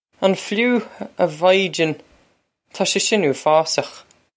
On flu? Ah Vy-jen…taw shay shin oo-a-faws-okh!
This is an approximate phonetic pronunciation of the phrase.